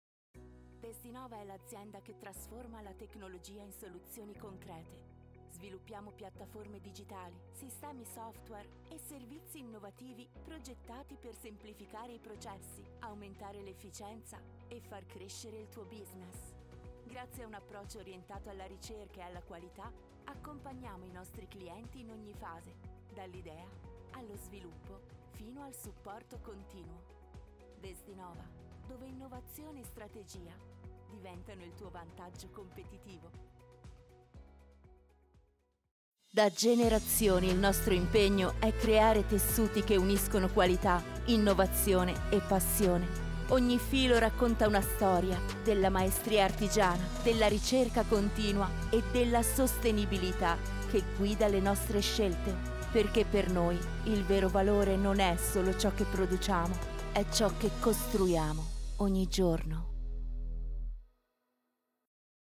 Young and lively voice, that can go from a soft sound, to a super enthusiastic tone.
Sprechprobe: Industrie (Muttersprache):
Neumann TLM 103 Focusrite Scarlett 2i2 4th gen ProTools